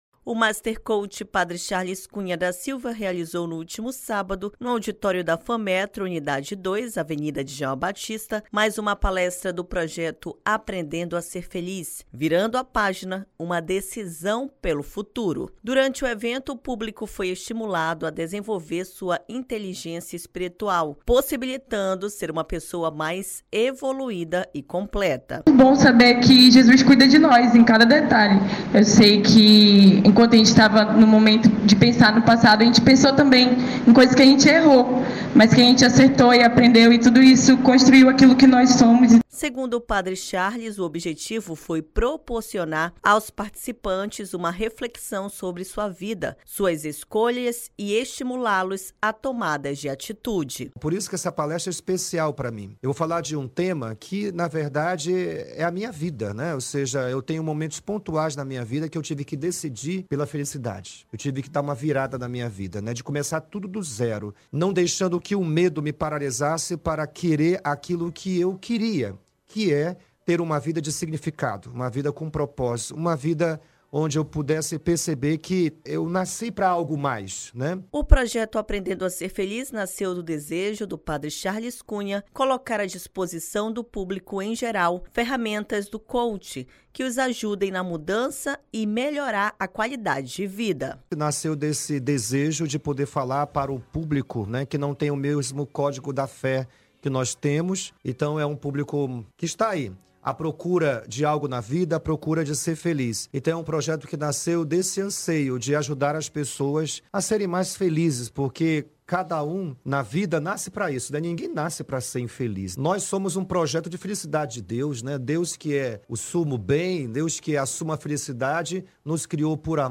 Palestra Virando a Página